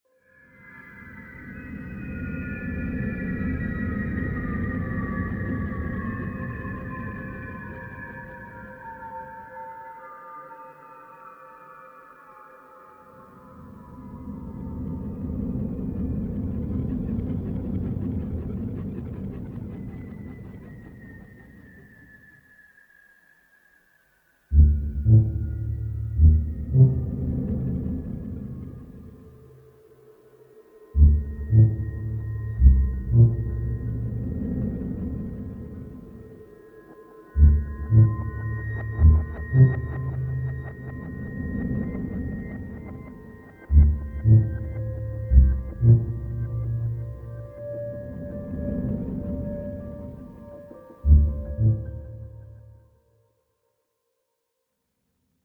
Theremin – ein elektronisches Instrument, das berührungslos gespielt wird und schwebende, sinusförmige Klänge erzeugt: von zarten Vibratos bis zu aufheulenden Glissandi. In Kombination mit akustischen Instrumenten entstehen Klänge, die Circes innere Zerrissenheit zwischen Göttlichkeit und Menschlichkeit spiegeln und von der Entdeckung ihrer Kraft berichten.